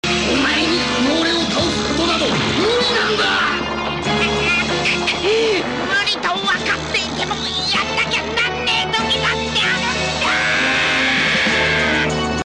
I love his Japanese voice...Masako Nozawa gives him such a carefree, almost childlike voice...it fits his character perfectly ^_^